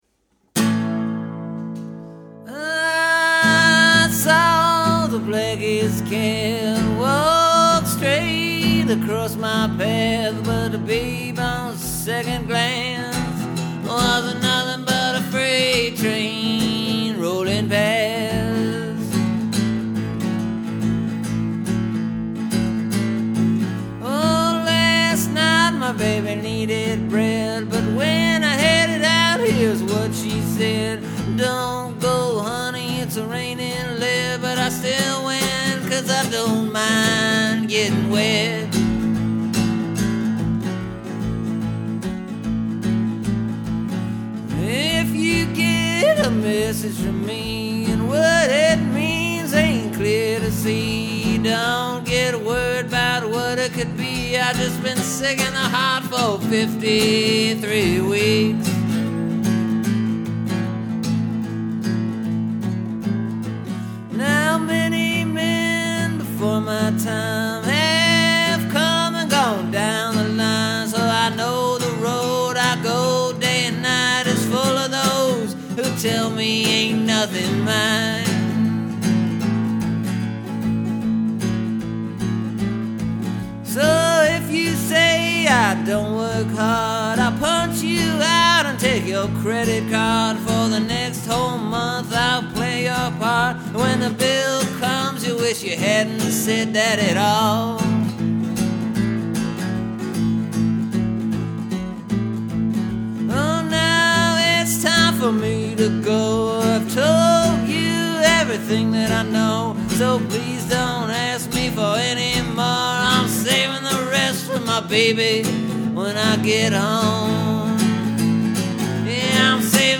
It’s just a straight up blues progression.
With these type of blues tunes I sometimes feel like there’s a chorus missing or something that needs to change in the song structure to loosen it up a bit. So it’s not feeling so similar and repetitive throughout.